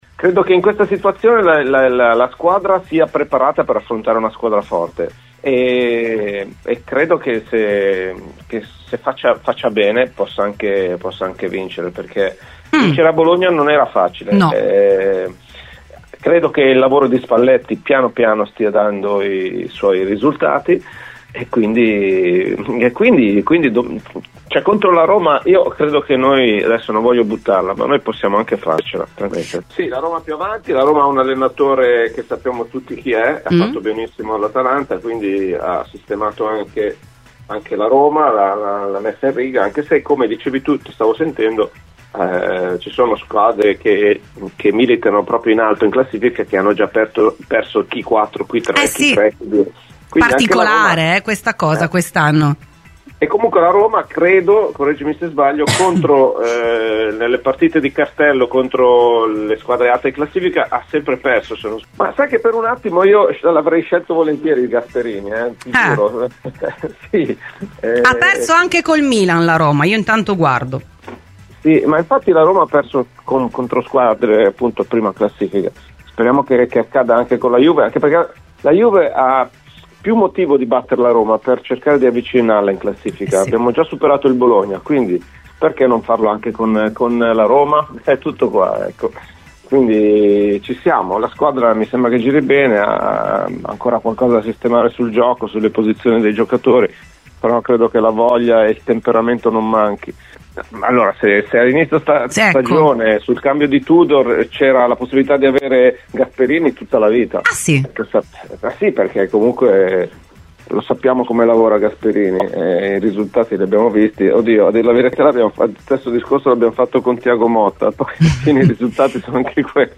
Mario Fargetta in esclusiva ai microfoni di Radio Bianconera: tra i temi trattati la Juve di Spalletti e la sfida di sabato contro la Roma di Gasperini
Mario Fargetta è intervenuto a Radio Bianconera, nel corso di Rbn Cafe.